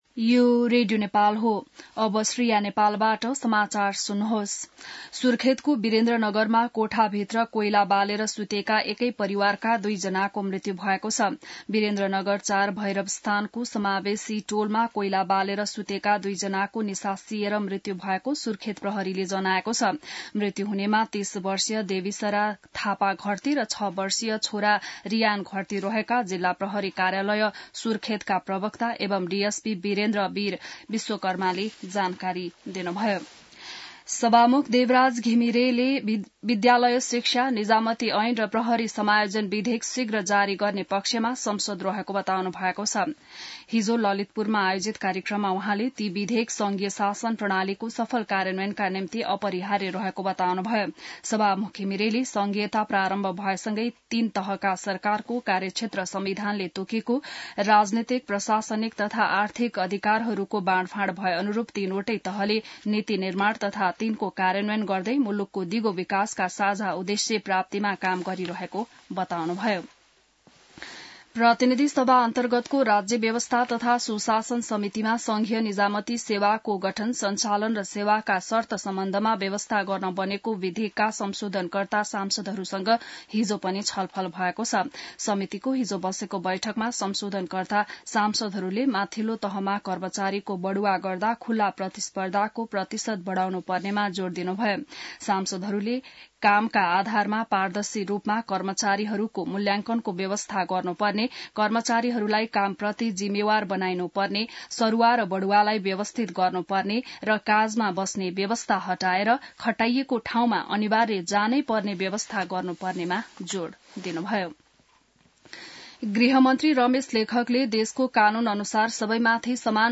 बिहान ६ बजेको नेपाली समाचार : ६ पुष , २०८१